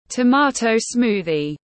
Sinh tố cà chua tiếng anh gọi là tomato smoothie, phiên âm tiếng anh đọc là /təˈmɑː.təʊ ˈsmuː.ði/
Tomato smoothie /təˈmɑː.təʊ ˈsmuː.ði/